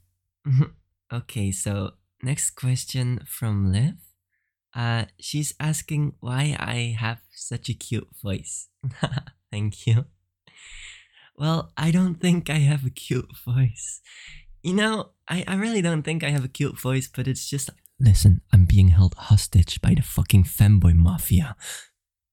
(but no serious the first part is my real speaking voice and I have to force to do the 2nd one)